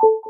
menuclick.wav